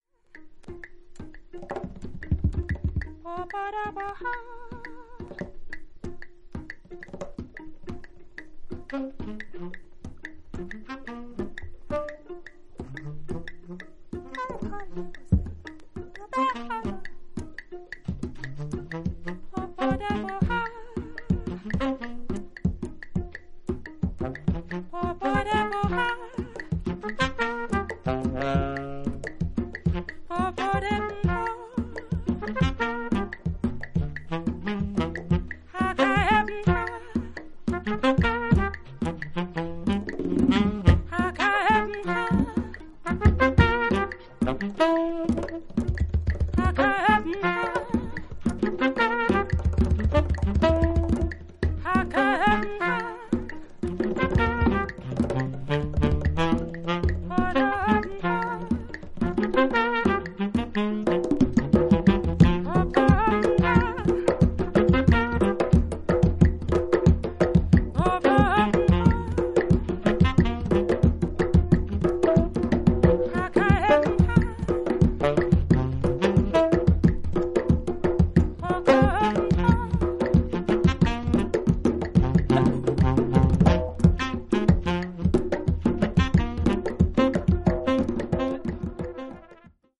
アンティル諸島出身のベーシスト/シンガー